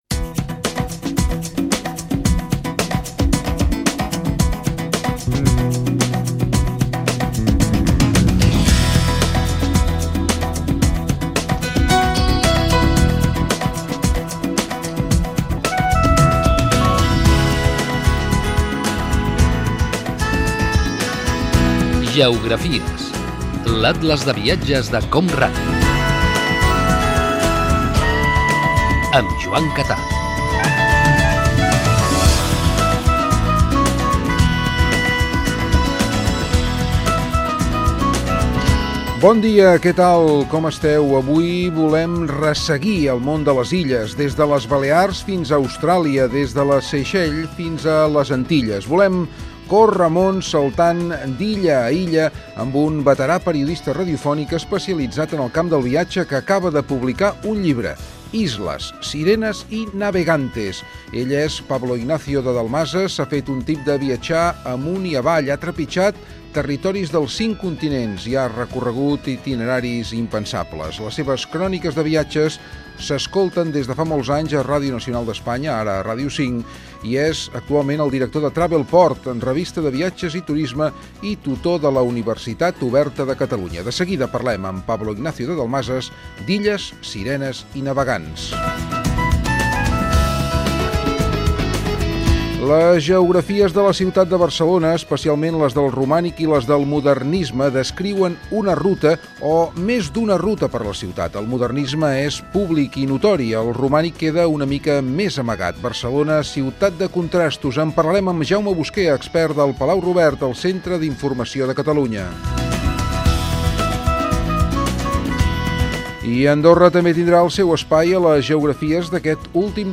Careta i sumari del programa
Divulgació
FM